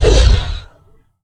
MONSTER Growl Deep 08b Short (mono).wav